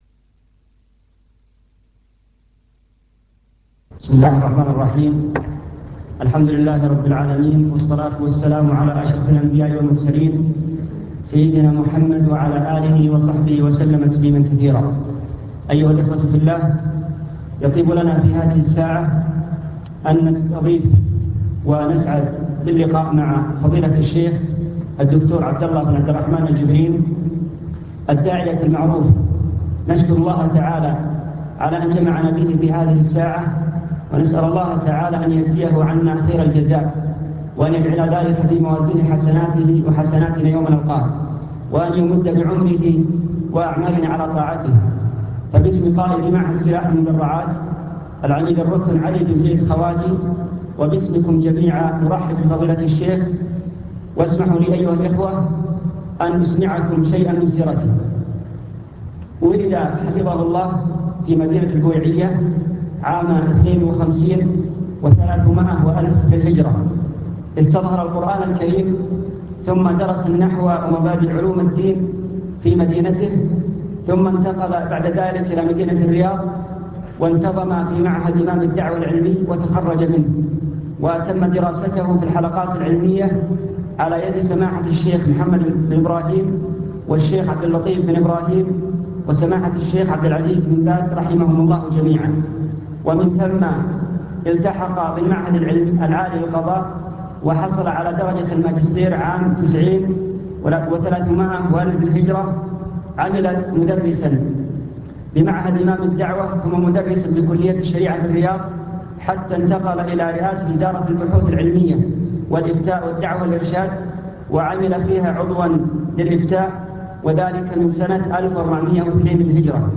المكتبة الصوتية  تسجيلات - لقاءات  كلمة في معهد سلاح المدرعات